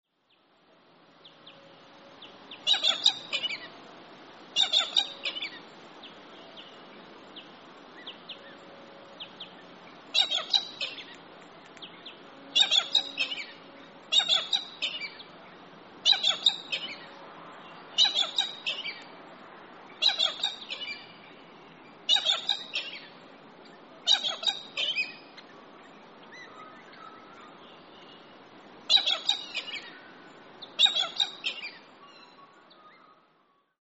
Galah - Cacatua roseicapilla
Voice: high pitched 'chill, chill', harsh screeching.
Call 2: a single bird calling
Galah_one.mp3